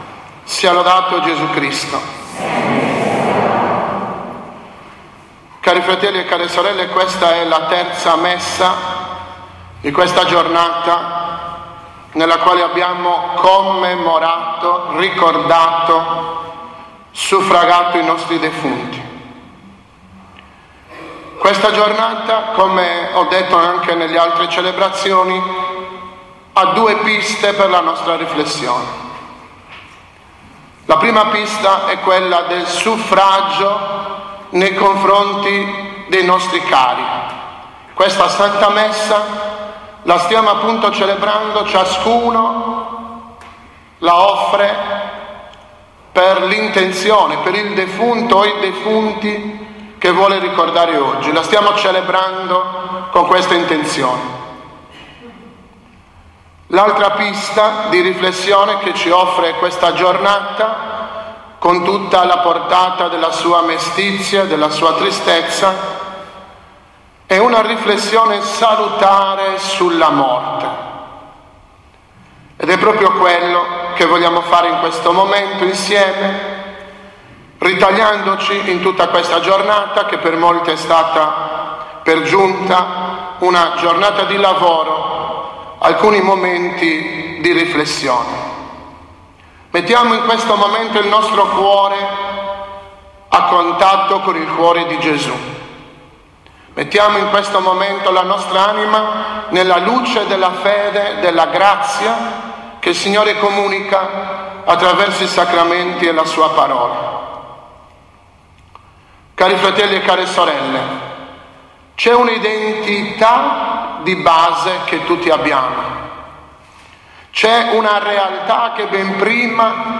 02.11.2016 – OMELIA DELLA COMMEMORAZIONE DEI FEDELI DEFUNTI